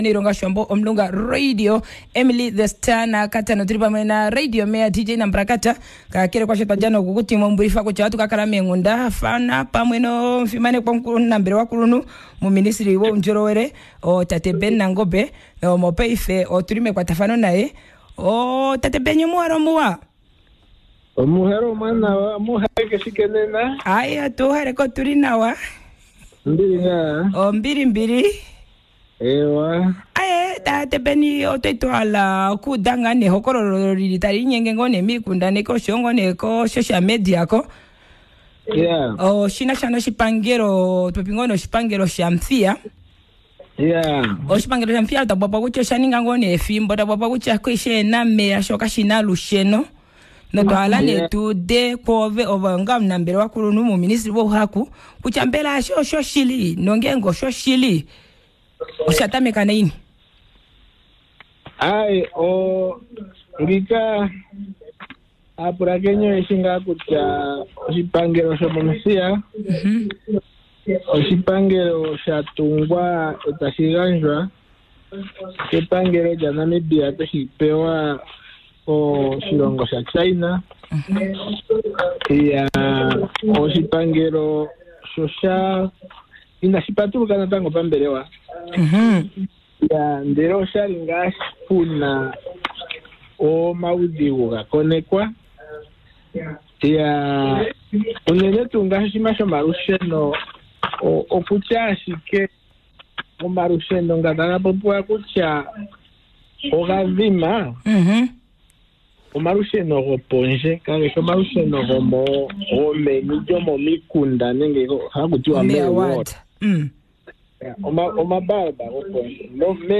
Lets hear if its true that lights are out at Omuthiya Hospital from the Executive Director in Ministry of Health Ben Nangombe on Easy Drive.